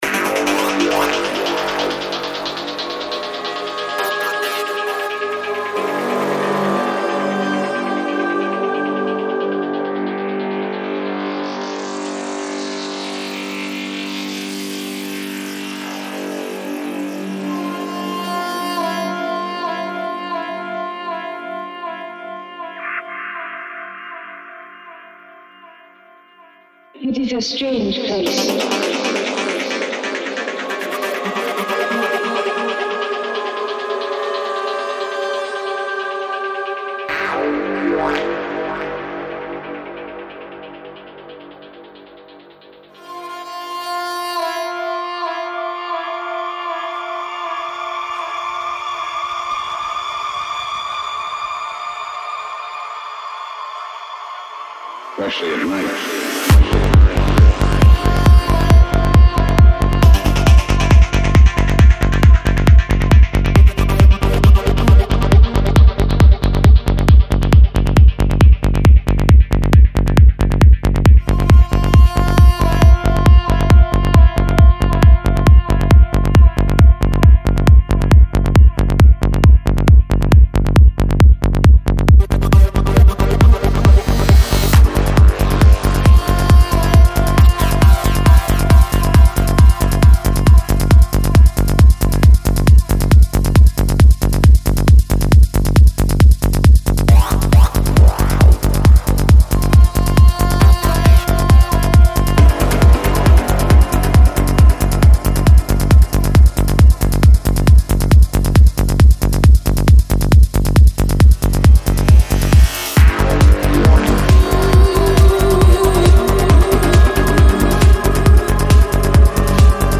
Жанр: Trance
21:52 Альбом: Psy-Trance Скачать 7.95 Мб 0 0 0